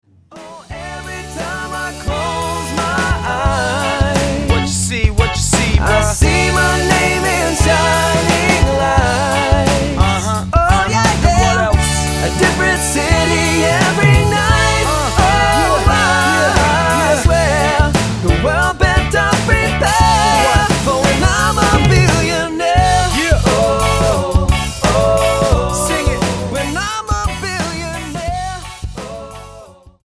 NOTE: Background Tracks 1 Thru 9